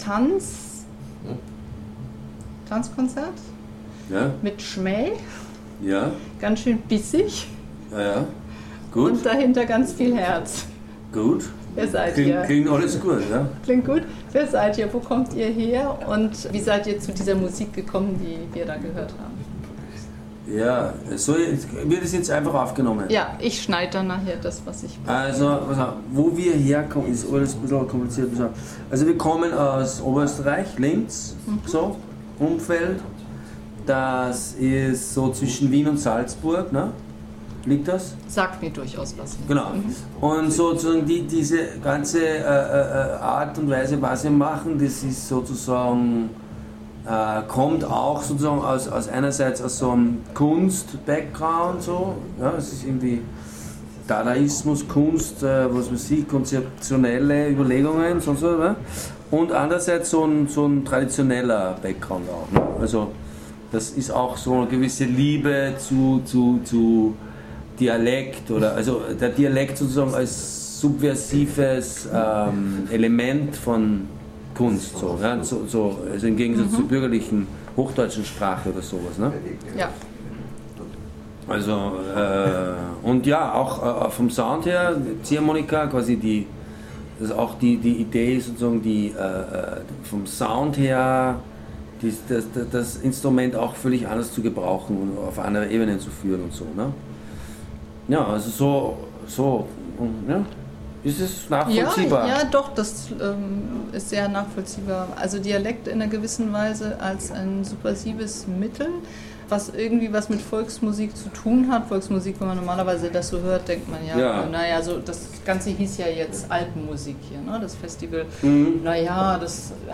Und das Styling ist sogar noch für das Interview wichtig, nach dem Duschen, es findet in letzter Minute doch statt, ich bin eigentlich schon längst gegangen.
Gespräch